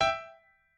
piano8_46.ogg